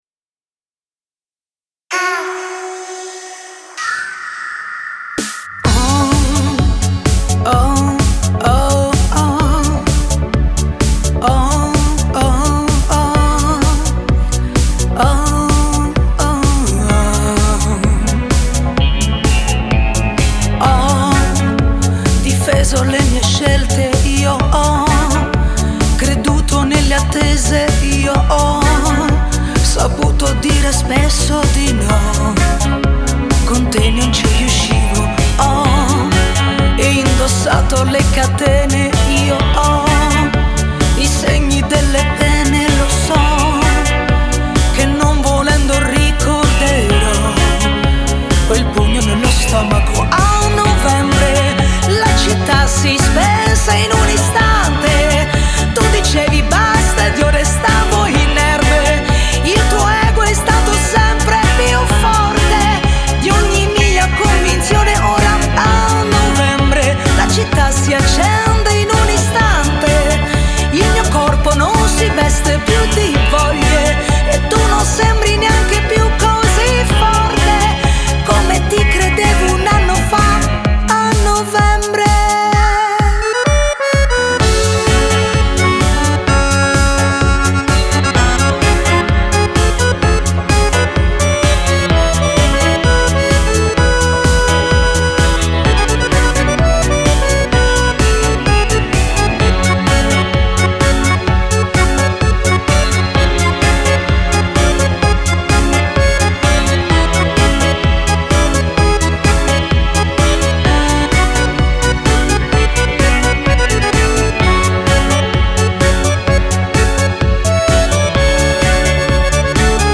(Ballo di gruppo)